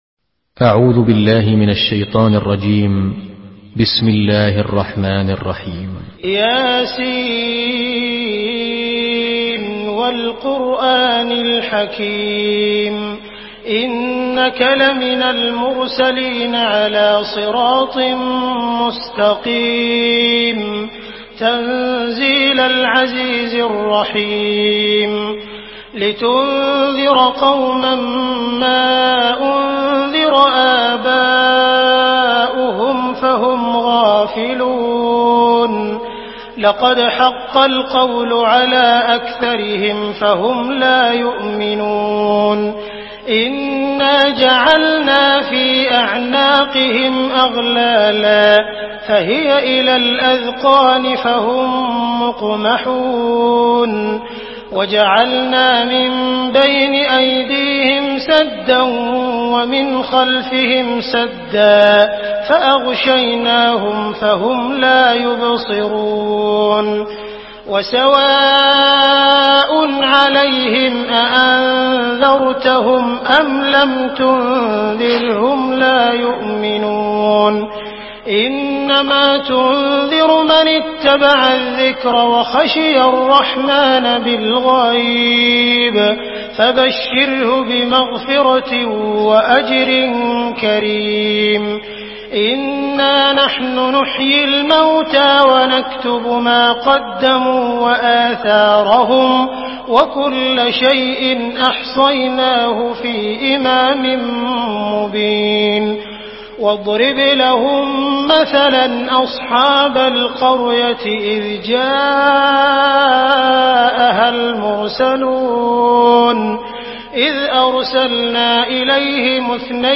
Surah Yasin MP3 by Abdul Rahman Al Sudais in Hafs An Asim narration.
Murattal